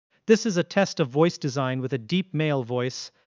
03_design_male_low — voice design, male low
영어(00_auto_en)는 꽤 자연스러운데, 한국어(01_auto_ko)는 톤이 많이 평평하고 억양이 어색한 느낌이 있어요. voice design 쪽은 female_british, male_low, whisper 모두 지정한 캐릭터가 어느 정도 구분돼 들리긴 해요.
AI, TTS
03_design_male_low.wav